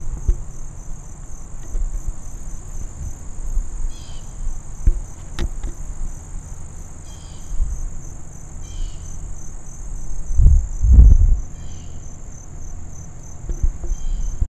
We ended up recording our sounds using the zoom voice recorder.
We messed with the input volume setting to try to capture more sound, especially when recording the non-human world (wildlife).
Non-human world (wildlife):